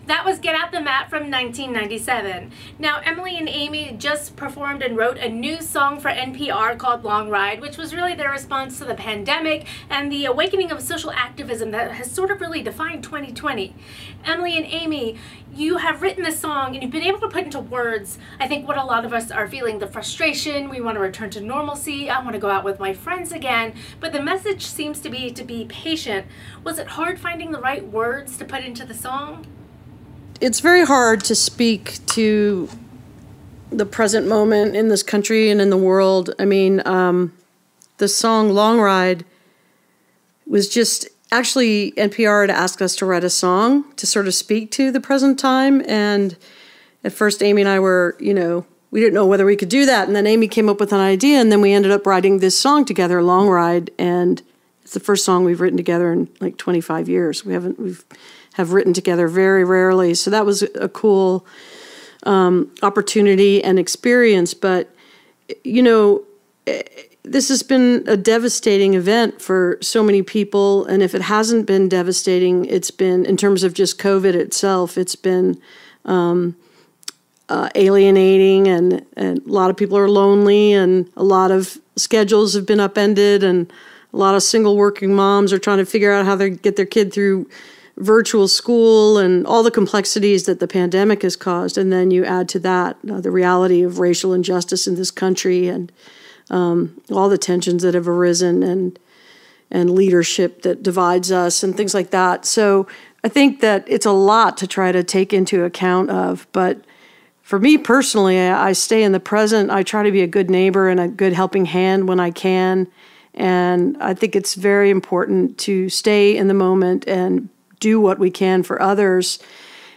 (captured from the youtube livestream)
06. interview (indigo girls) (4:52)